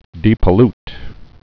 (dēpə-lt)